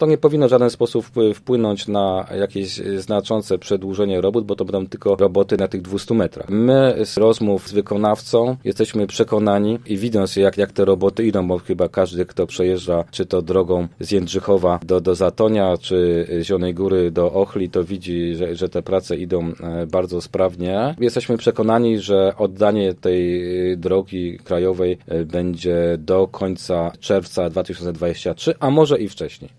W związku z wykryciem kurhanów i grobów z epoki brązu na odcinku 200m budowanej drogi trwają wykopaliska. Jednak odkrycia archeologiczne nie wpłyną na przebieg trasy. – Badania archeologów nie powinny także znacząco wpłynąć na terminowe wykonanie prac – mówi Krzysztof Kaliszuk, wiceprezydent Zielonej Góry: